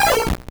Cri de Canarticho dans Pokémon Or et Argent.